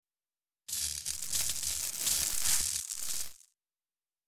Звуки прибора ночного видения
Когда с помощью прибора ночного видения смотришь на огонь или на свет